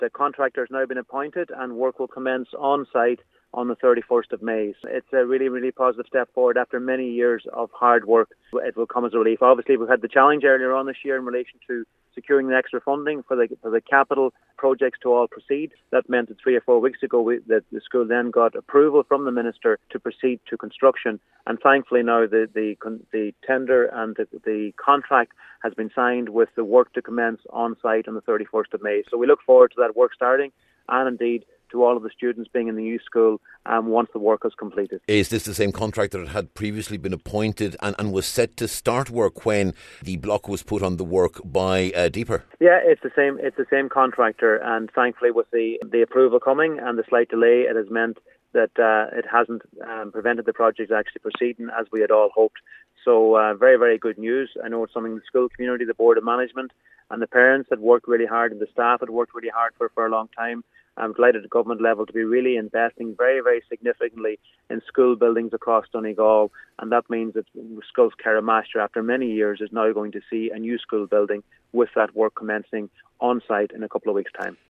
Now, Minister Charlie McConalogue says the work will begin on May 31st……..